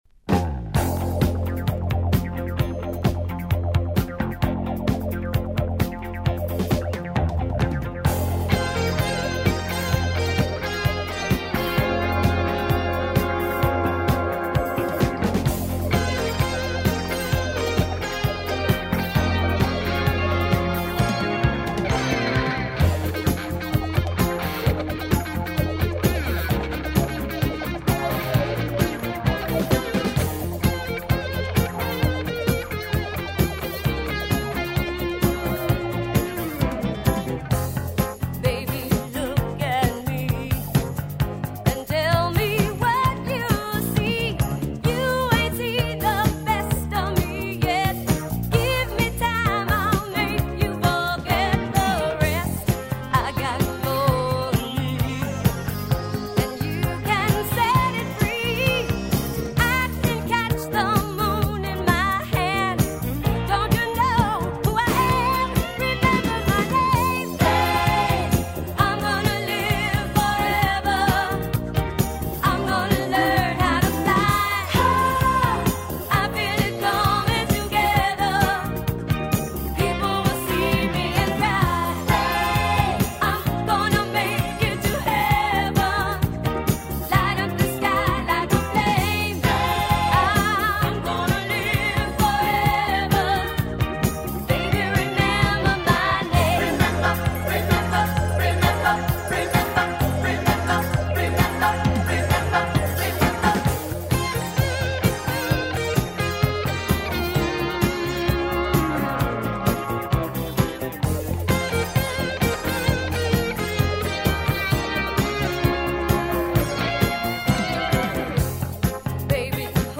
Lots of Deepness going on in here so sit back and enjoy!